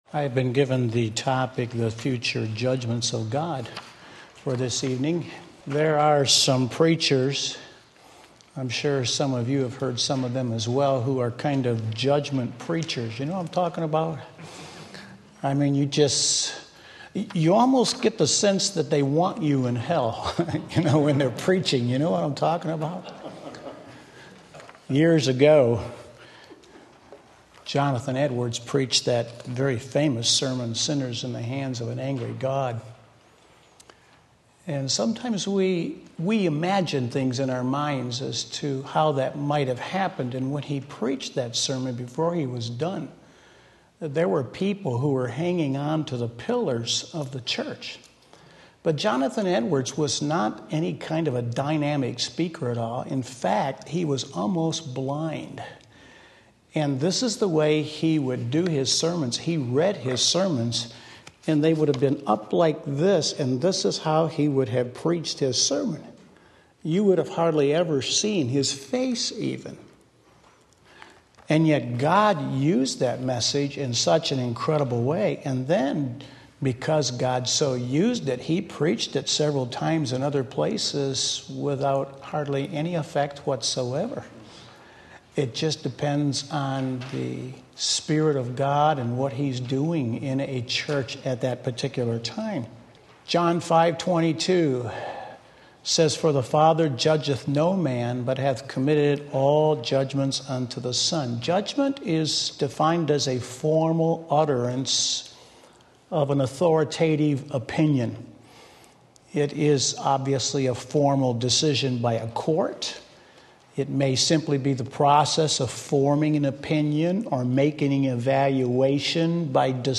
Sermon Link
Wednesday Evening Service